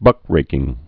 (bŭkrākĭng)